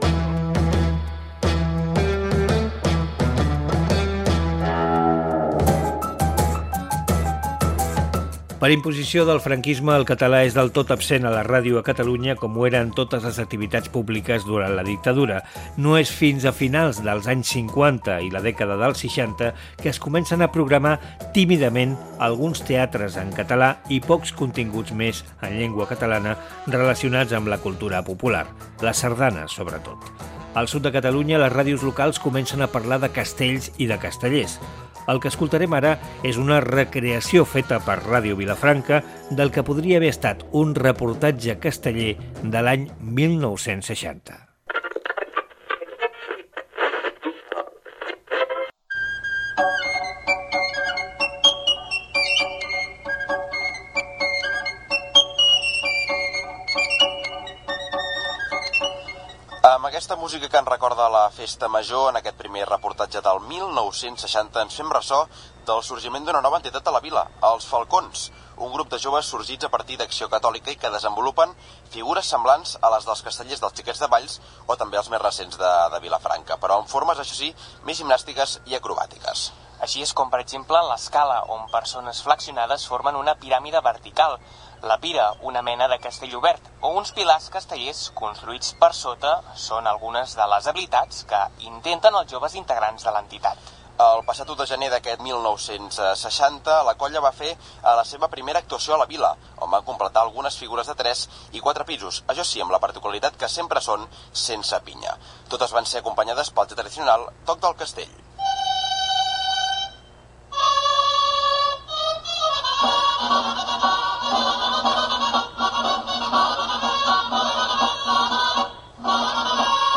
02bac1c425763e950710ddc8f2606e77fc7f15a3.mp3 Títol La Xarxa Emissora Ràdio Vilafranca Cadena La Xarxa Titularitat Pública nacional Nom programa La ràdio, històries de 100 anys Descripció Recreació ficcionada d'un reportatge casteller a l'any 1960. Publicitat dels magatzems barcelonins "Jorba". Recreació d'un informatiu sobre l'assemblea antifranquista a Vic.